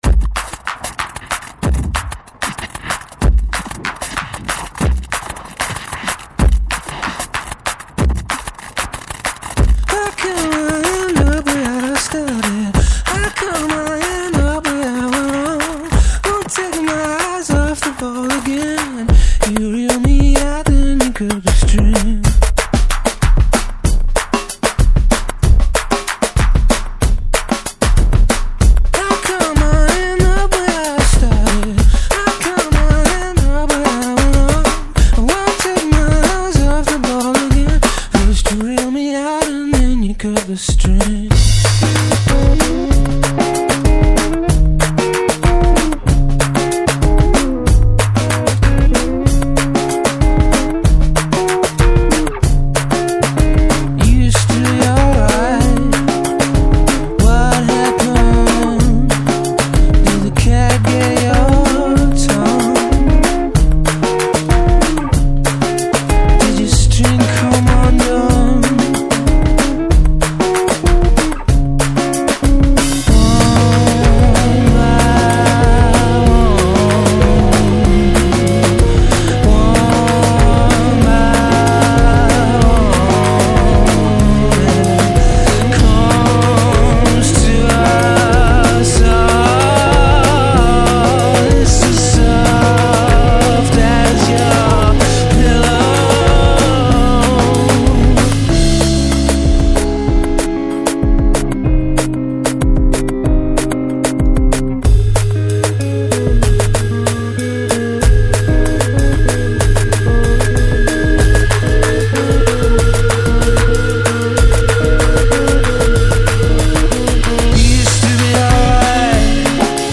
Alternative Rock, Art Rock